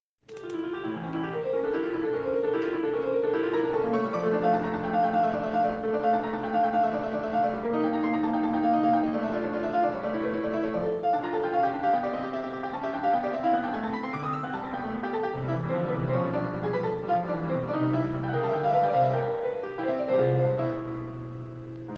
Also, the quality is pretty poor, because I recorded it with my smartphone, to show it some friends.